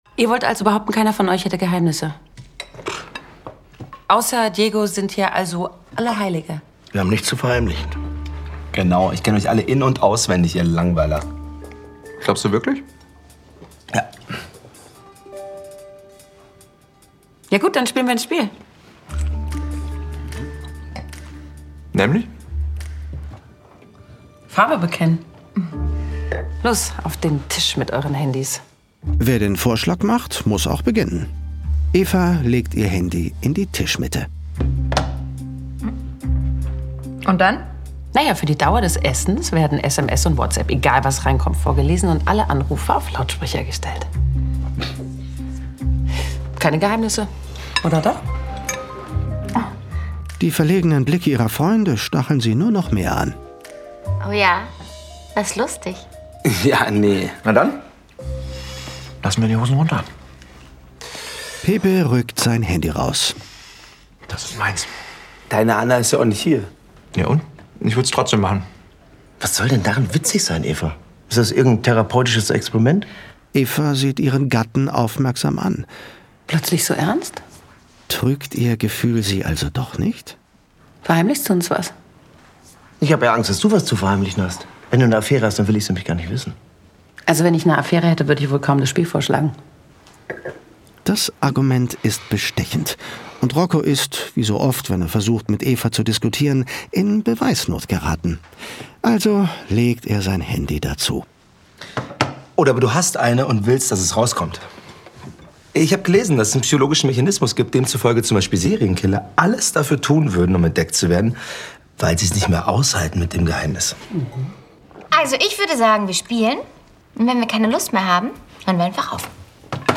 Das Original-Hörspiel zum Film
Er lässt einen Abend unter Freunden eskalieren und bringt dafür ein einmaliges Star-Ensemble an den Tisch: Elyas M'Barek, Karoline Herfurth, Florian David Fitz, Jella Haase, Wotan Wilke Möhring, Jessica Schwarz und Frederik Lau.